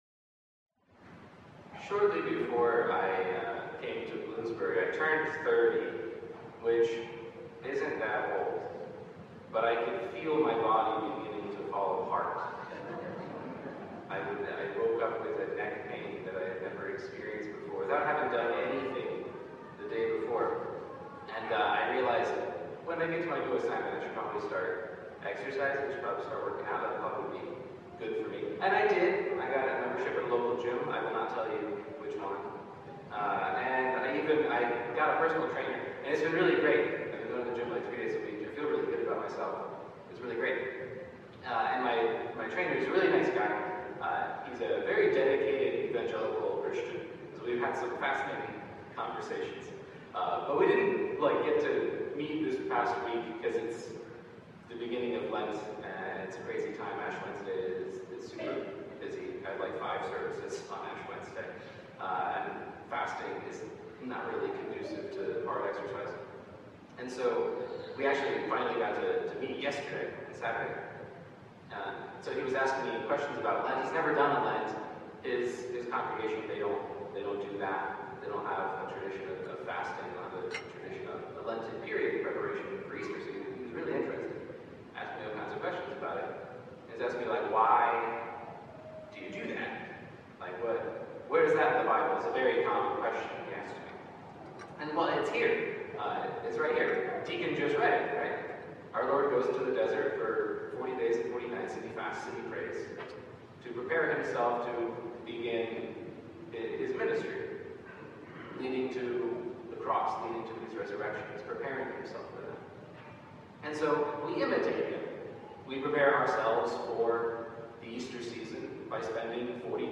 homilies-4.mp3